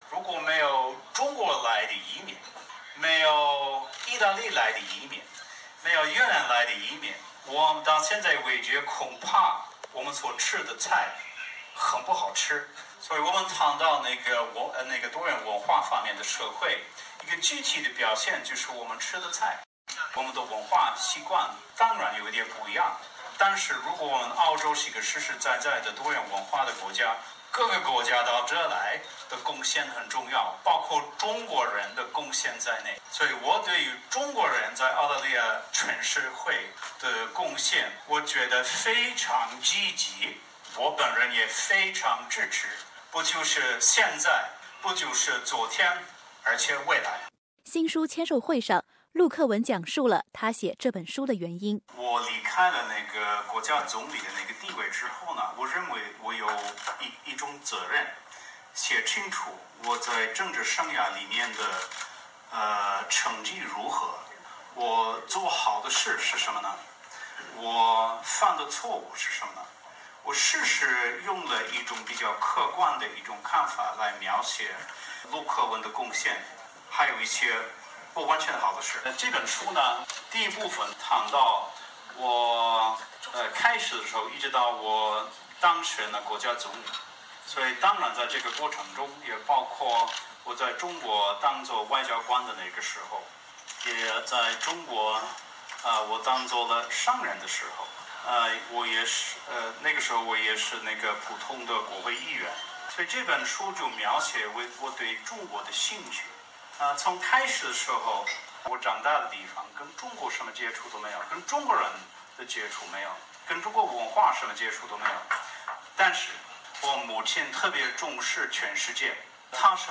上周六， 他的首场华人签售会在悉尼Chatswood举办，吸引了很多民众前往参加。
陆克文当天也用流利的中文回答了所有华人媒体记者的提问。